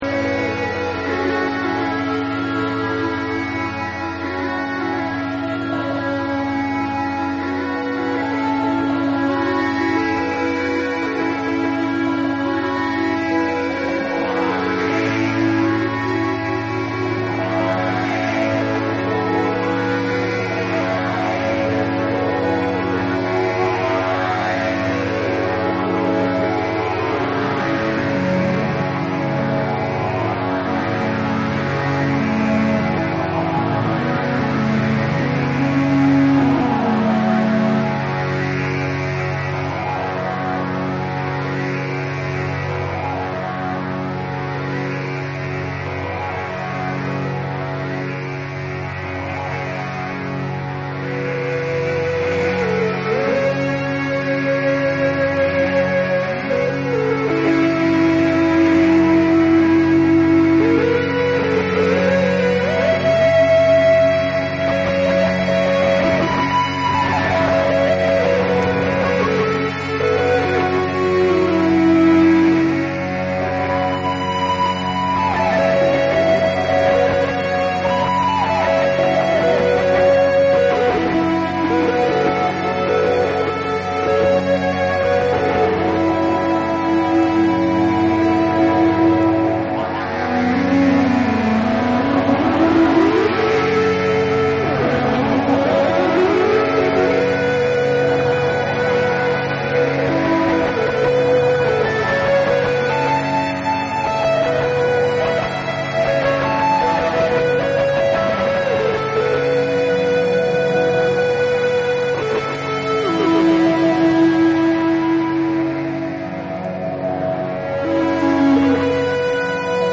NEW AGE & OTHERS